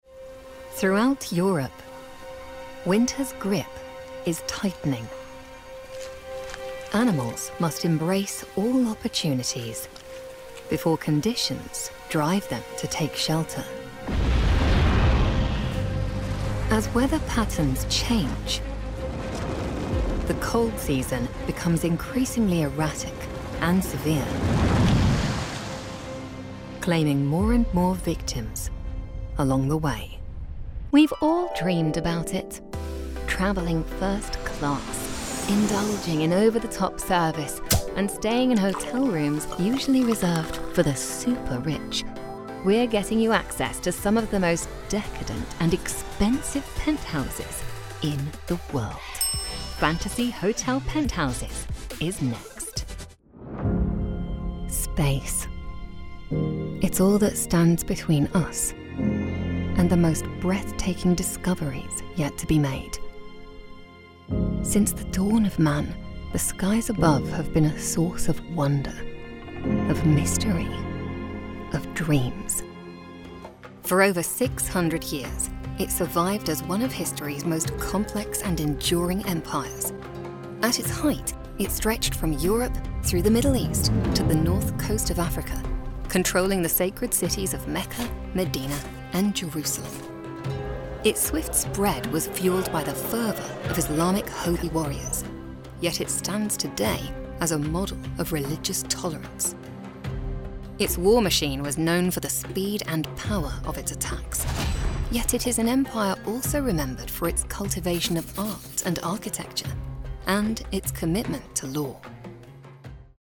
Inglés (Reino Unido)
Documentales
Micrófono de condensador Neumann TLM 103
Cabina insonorizada con calidad de transmisión con paneles acústicos GIK